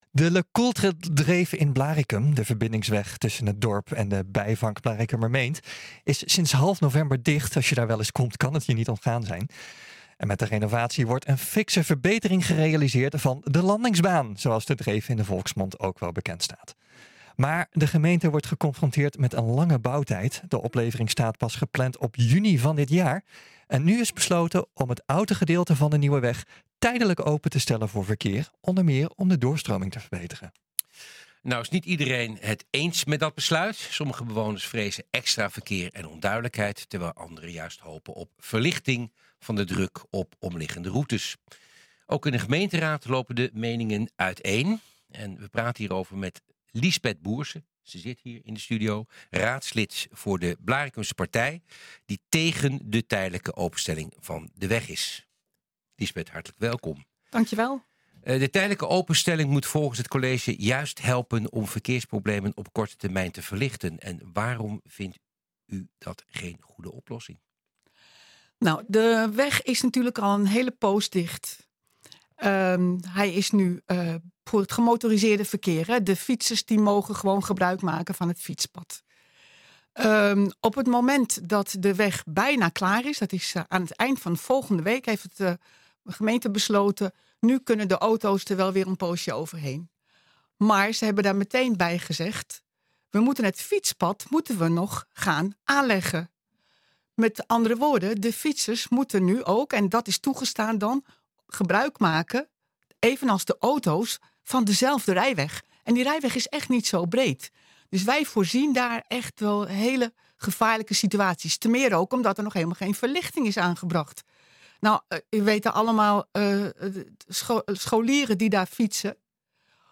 We praten hierover met Liesbeth Boersen, raadslid voor de Blaricumse Partij, die tegen de tijdelijke openstelling van de weg is.